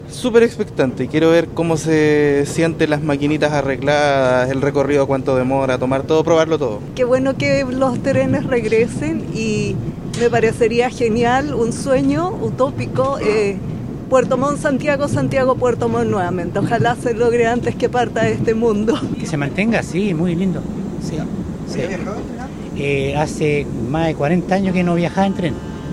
pasajeros-tren-llanquihue.mp3